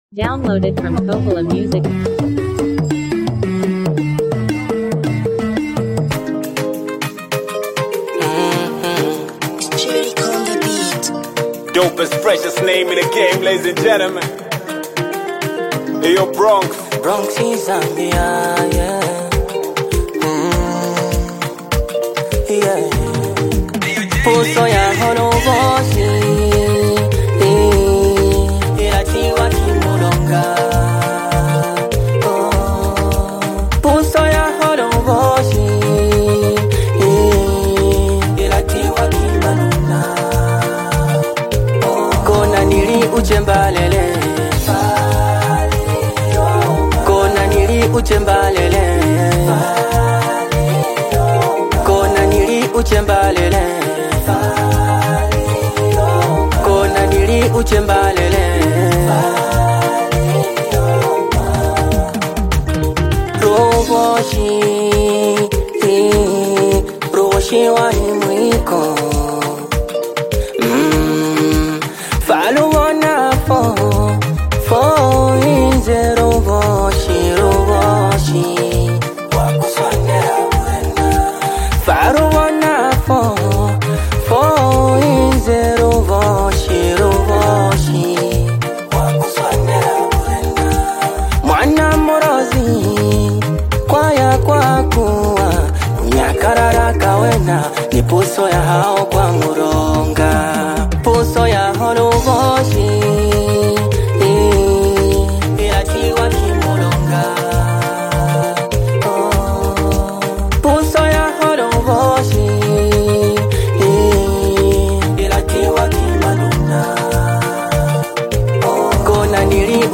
Zambian hip-hop
blends street-inspired lyricism with mainstream appeal
signature confident flow and polished delivery